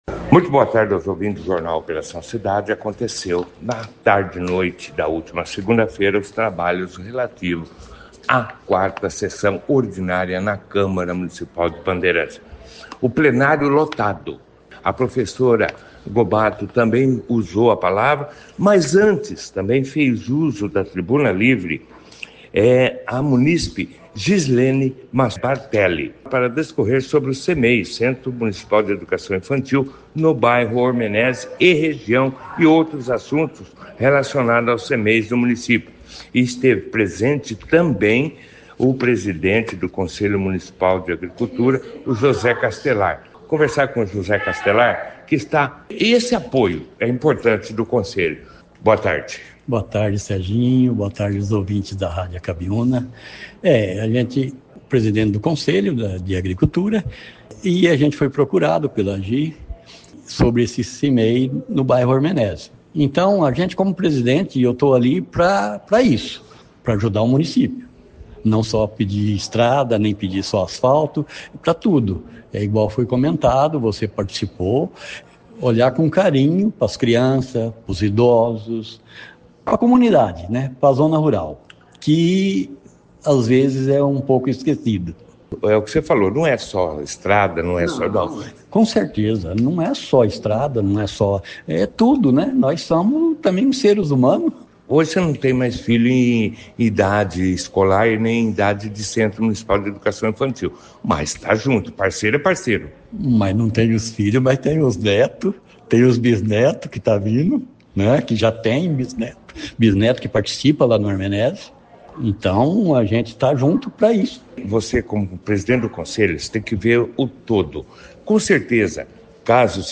O destaque do Jornal Operação Cidade desta terça-feira (03) foi a quarta sessão ordinária da segunda sessão legislativa, da décima nona legislatura, que aconteceu ontem segunda feira (02), na Câmara Municipal de Bandeirantes.